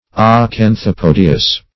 Search Result for " acanthopodious" : The Collaborative International Dictionary of English v.0.48: Acanthopodious \A*can`tho*po"di*ous\, a. [Gr.
acanthopodious.mp3